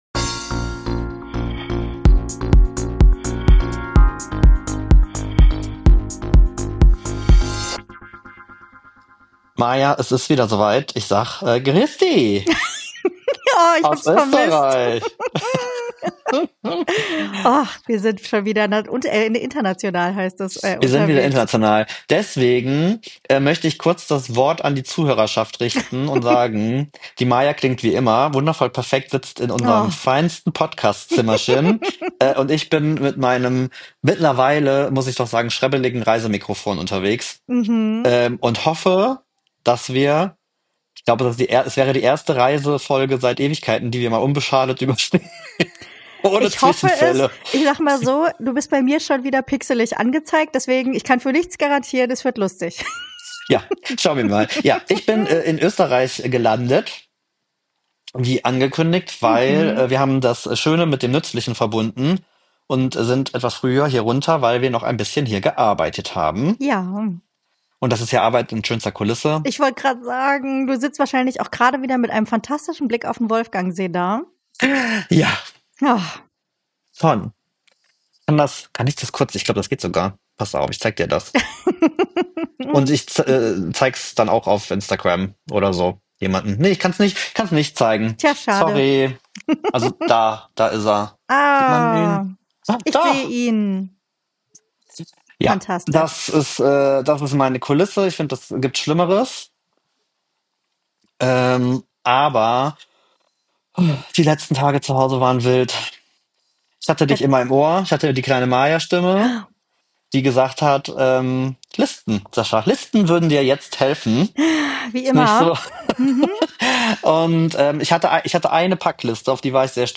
Und es klingt so, als ob er über ein Dosentelefon anrufen würde...